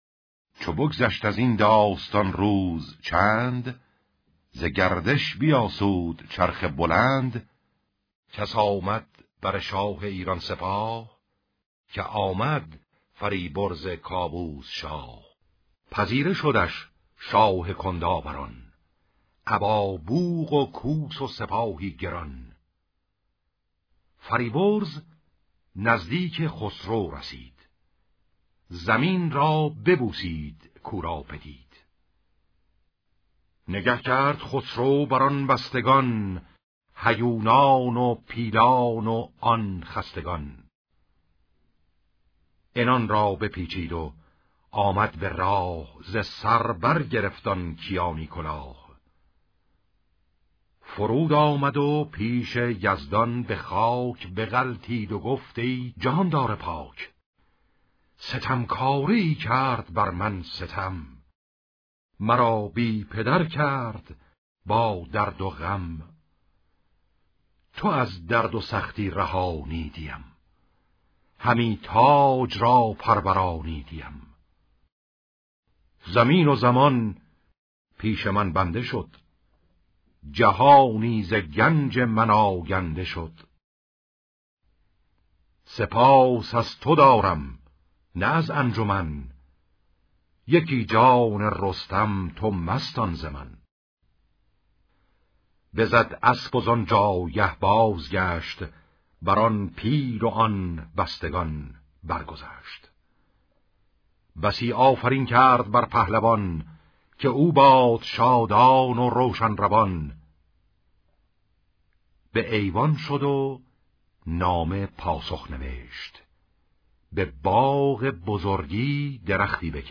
شاهنامه خوانی